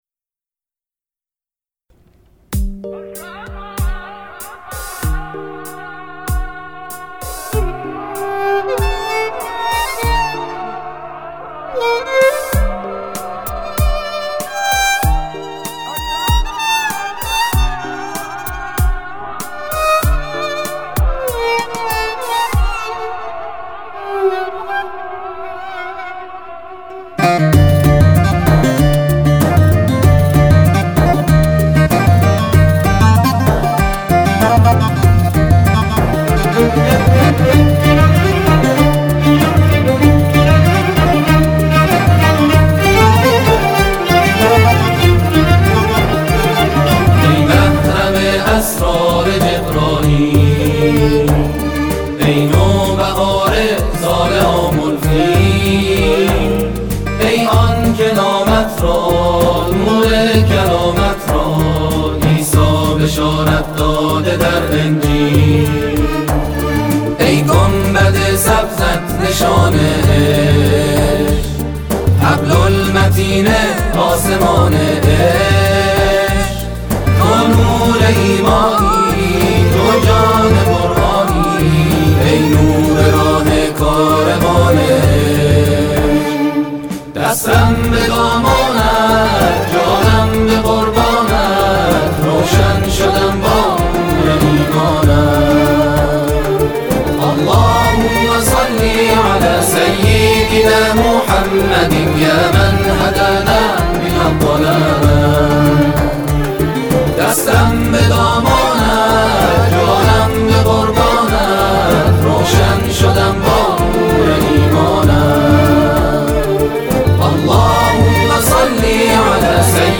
گروه تواشیح، همخوانی و مدیحه‌سرایی
متشکل از قاریان و حافظان قرآن کریم